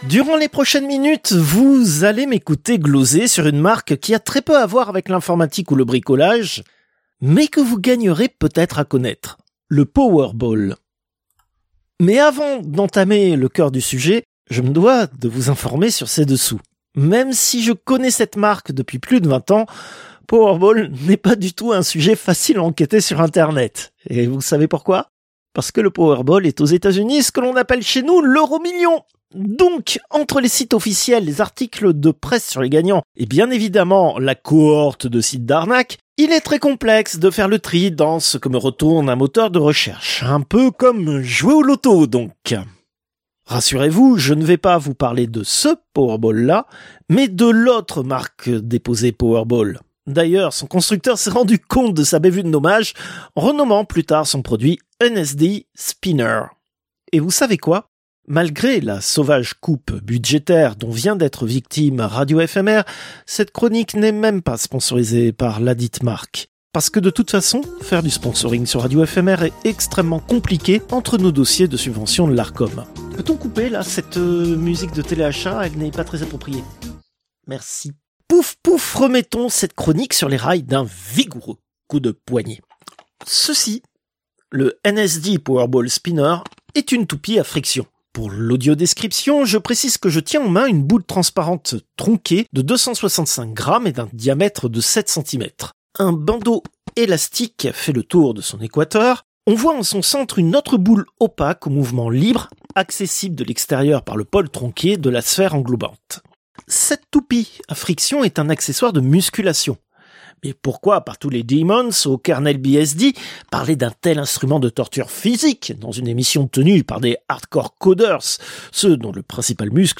Extrait de l'émission CPU release Ex0231 : lost + found (février 2026).